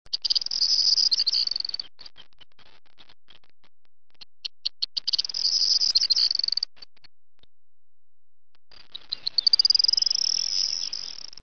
Chincha (Trigueirão - Miliaria Calandra)
triguero.wav